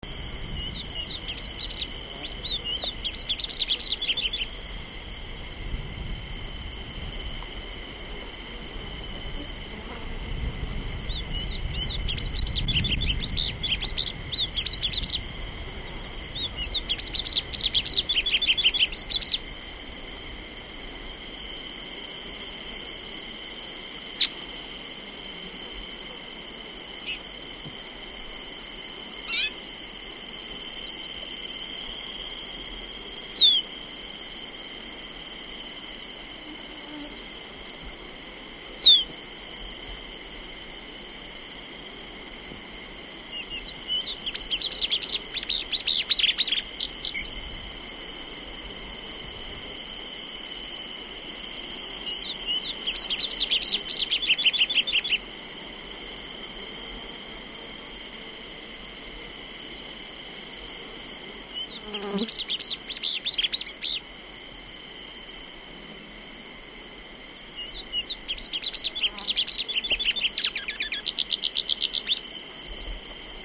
PLUMBEOUS SEEDEATER Sporophila plumbea
Song by male recorded Aguara-Ñu, Mbaracayú Biosphere Reserve, Departamento Canindeyú